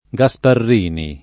[ g a S parr & ni ]